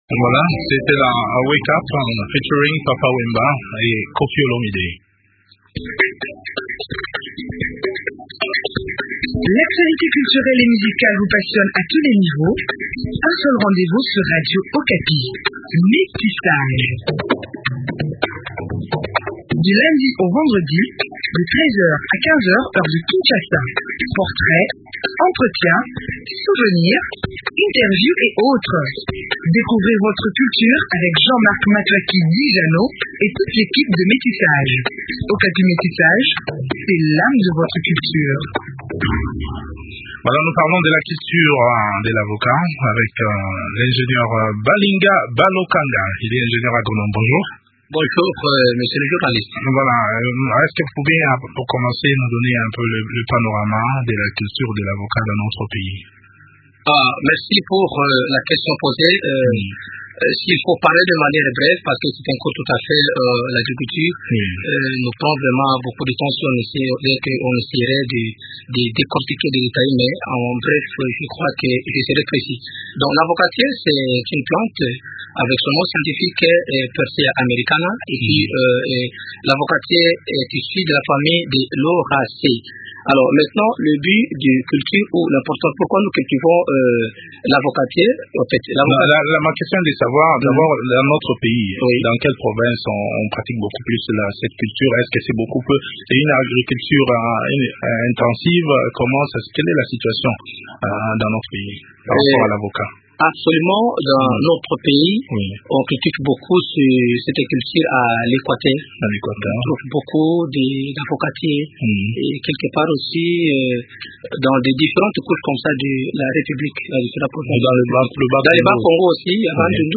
Quelles sont les techniques appropriées pour la culture de l’avocat ? Eléments de réponse dans cet entretien